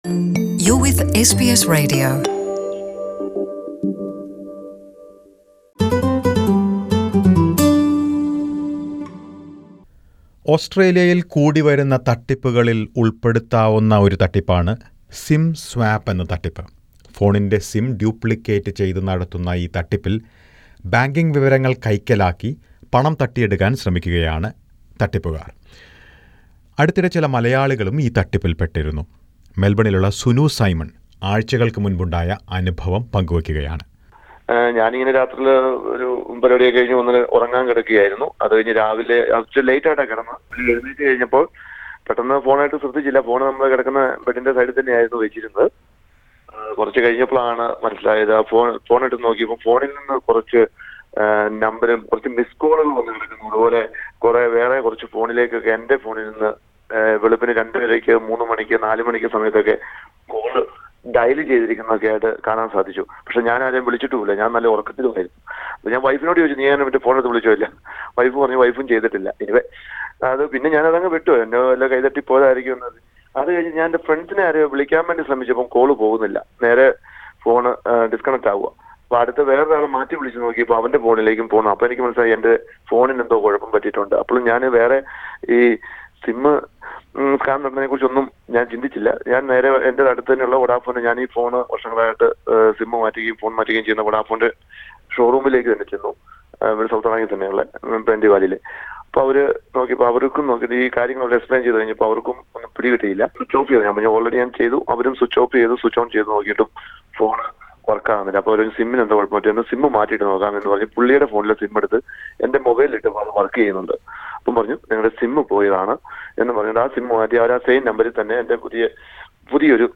സമയോചിതമായ ഇടപെടലിലൂടെ പണം നഷ്ടമാകുന്നതിൽ നിന്ന് രക്ഷപ്പെട്ട ഒരു മലയാളിയുടെ അനുഭവം കേൾക്കാം.